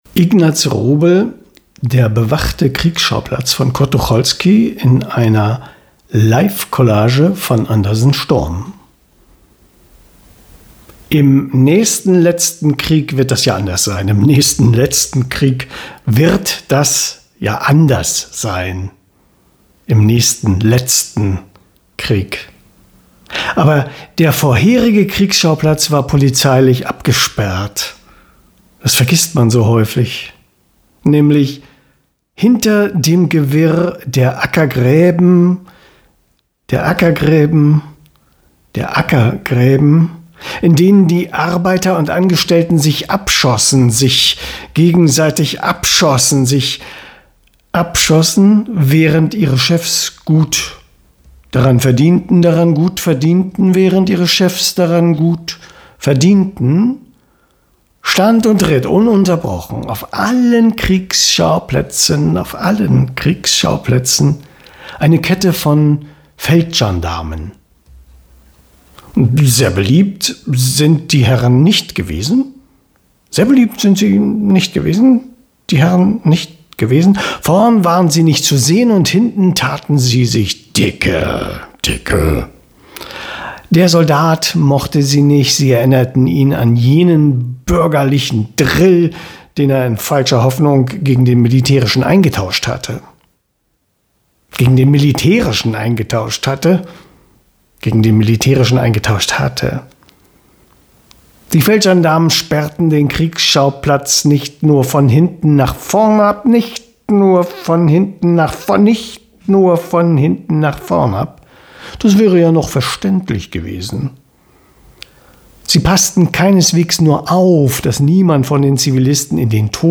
Kriegsschauplatz – Live-On-Mic-Collage nach Tucholsky
Spontane „Live-On-Mic-Collage“.
kriegsschauplatz-live-on-mic-collage-nach-tucholsky.mp3